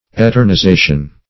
Meaning of eternization. eternization synonyms, pronunciation, spelling and more from Free Dictionary.